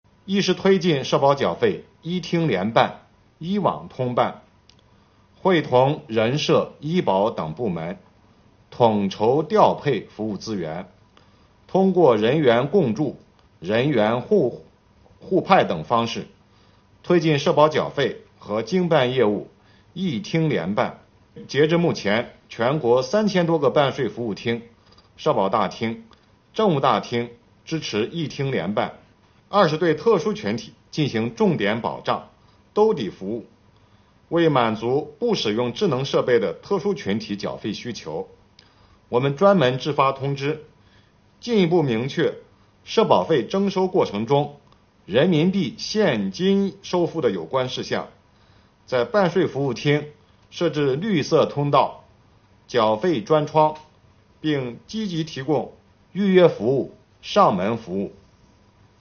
7月26日，国家税务总局召开新闻发布会，就税收大数据反映经济发展情况、税务部门学党史办实事扎实推进办税缴费便利化、打击涉税违法犯罪等内容进行发布并回答记者提问。